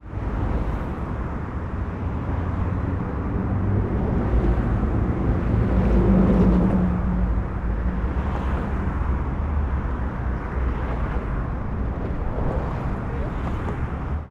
Vinduederkunharenkeltglas
vinduederkunharenkeltglas.wav